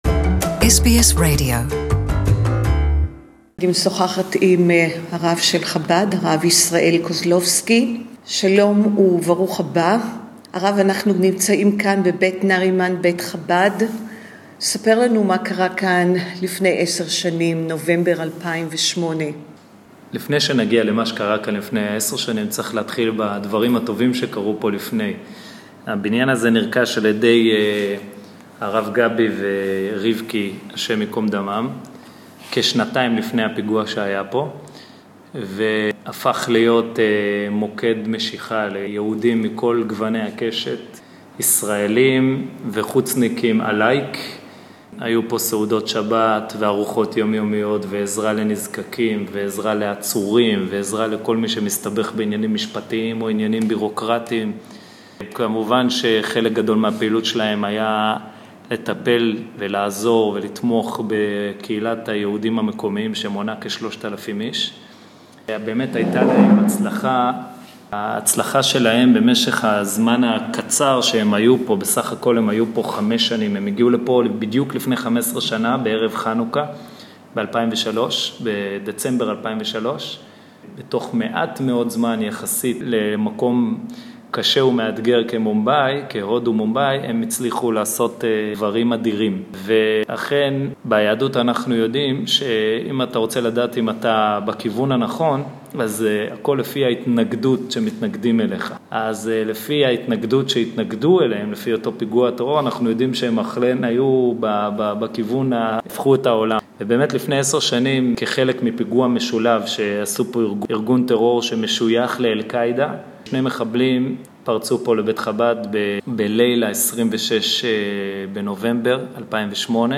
He wanted to show me the center and the special memorial they built for all the 176 victims murdered in the attack including the Rabbi and his wife. I interviewed the young Rabbi there and then and will bring you the interview today in Hebrew (Hebrew)